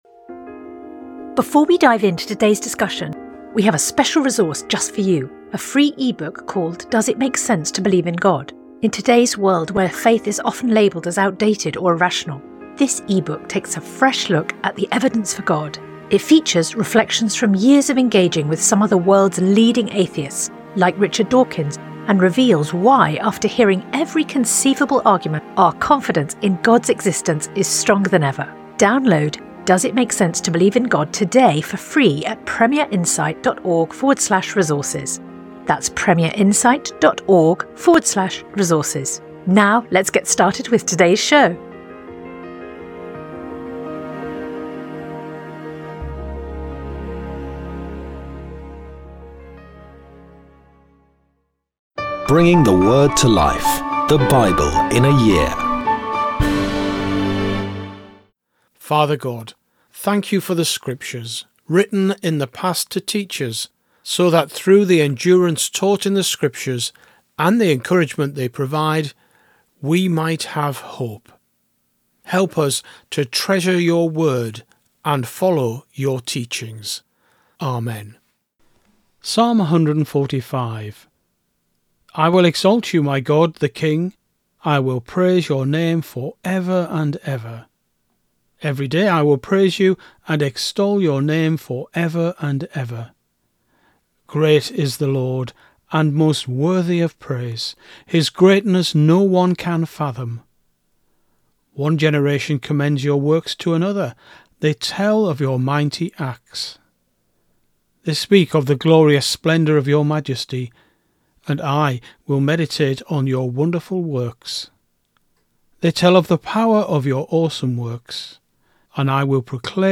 Today’s readings come from Hosea 7; Romans 11; Psalms 145 Sponsored ad Sponsored ad